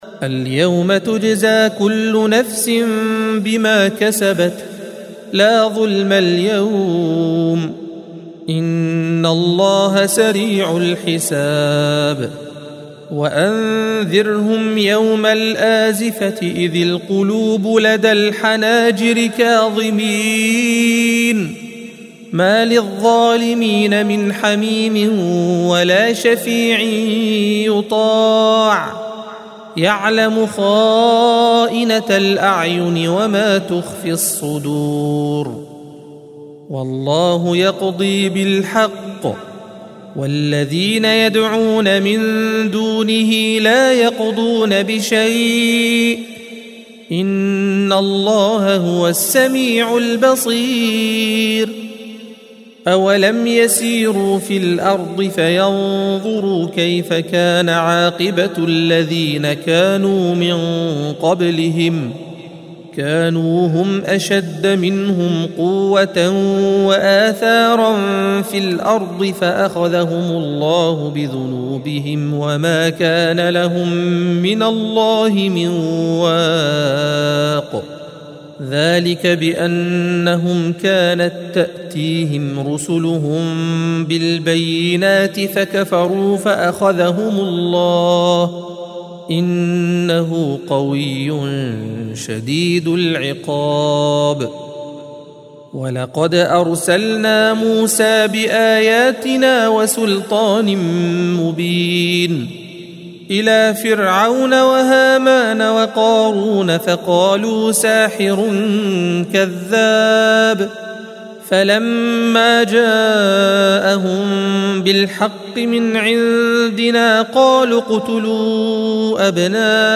الصفحة 469 - القارئ